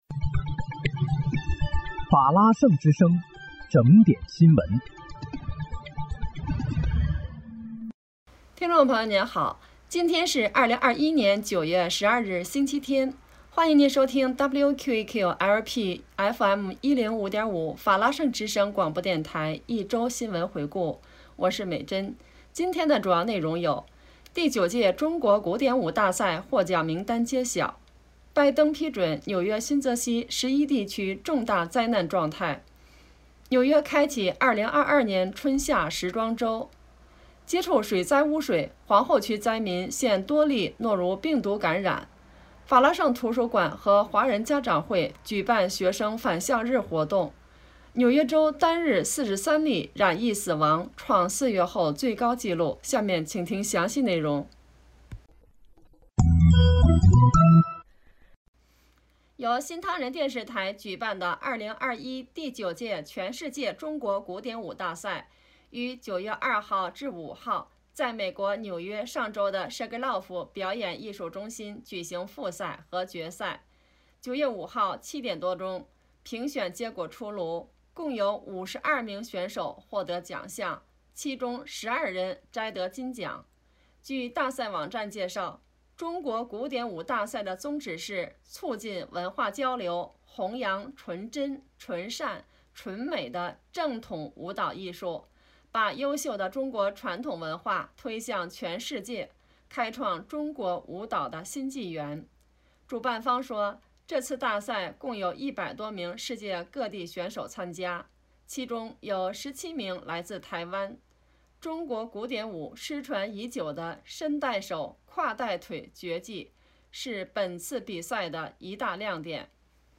9月12日（星期日）一周新闻回顾